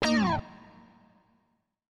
House / Guitar / ELECGUIT065_HOUSE_125_A_SC2(R).wav